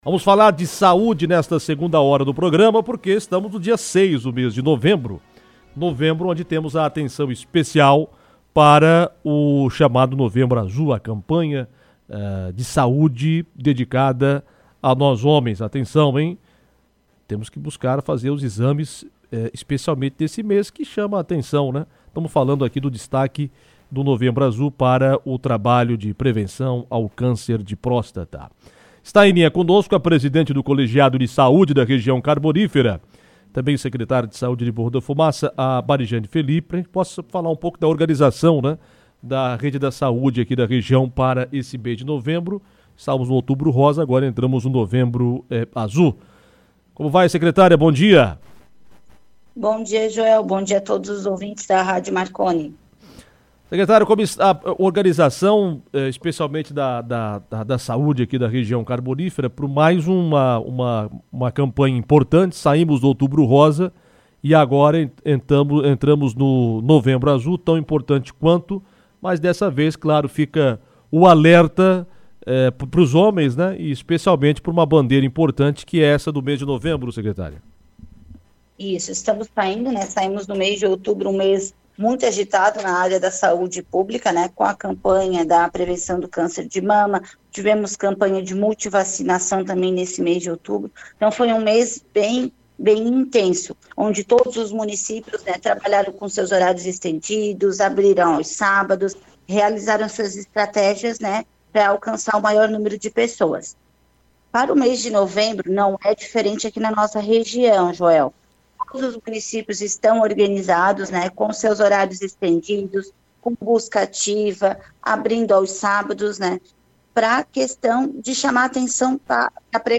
Isso porque as cidades continuam com as ações, agora com a campanha do Novembro Azul, que alerta sobre o câncer de próstata e a saúde masculina. De acordo com a presidente do Colegiado de Saúde da Amrec e secretária de Saúde de Morro da Fumaça, Marijane Felippe , as unidades de cada município estarão atendendo em horário estendido para que os homens possam realizar as suas consultas e exames de rotina. O assunto foi destaque em entrevista no programa Comando Marconi .